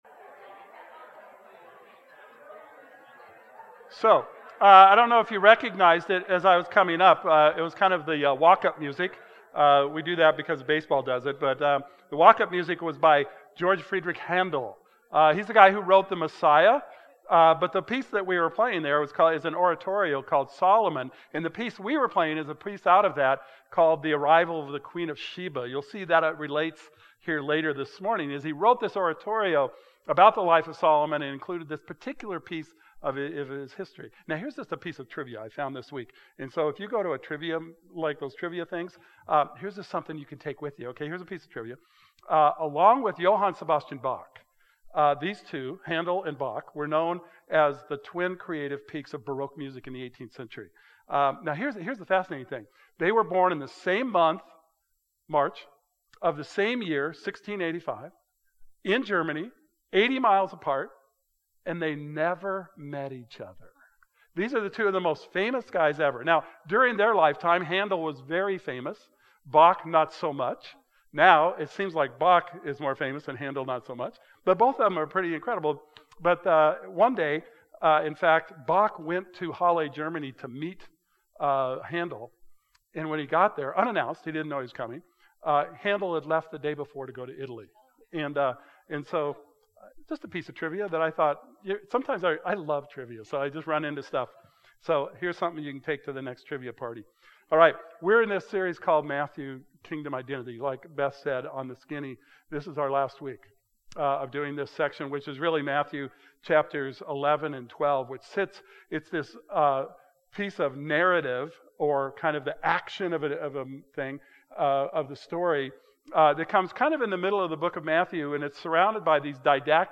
Today's message finishes the last of Matthew 12 and the series, Matthew: Kingdom Identity.